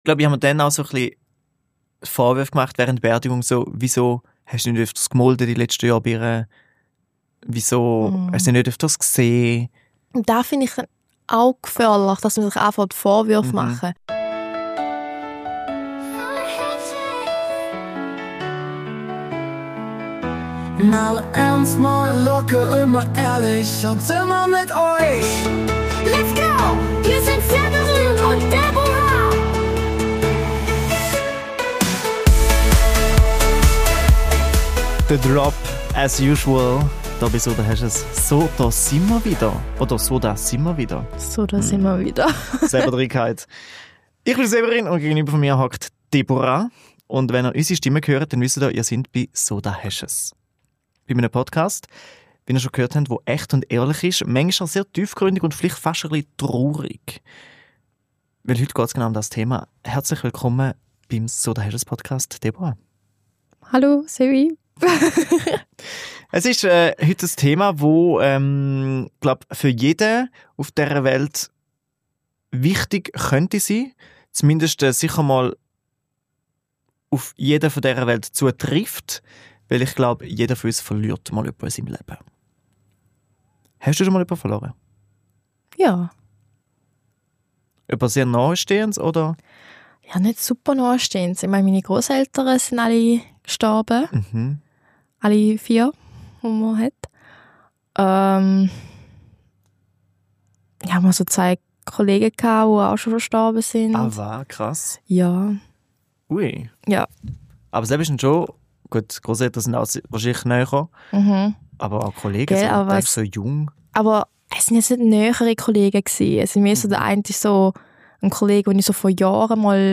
Ausserdem klärt uns eine Trauerbegleiterin auf, wie wir künftig vorbereitet sind, wenn eine Todesmeldung kommt.